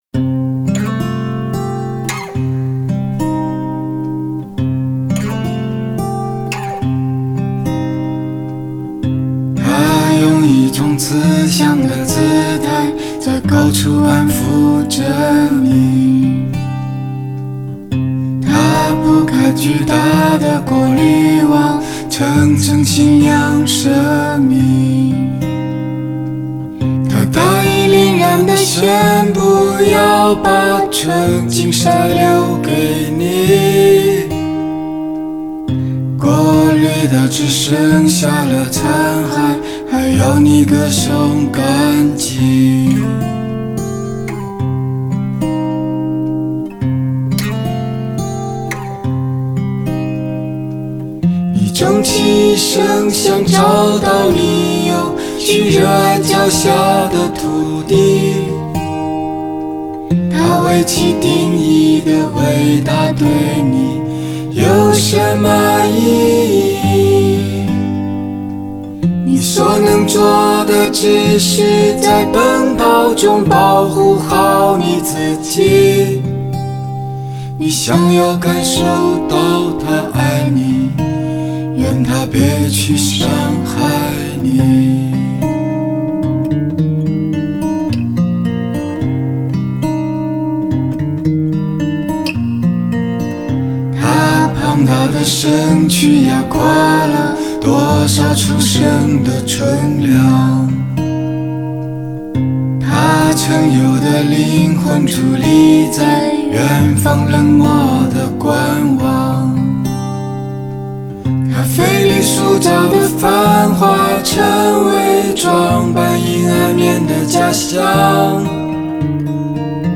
中国民谣诗人，全能创作型歌手，独立音乐人。
纯乐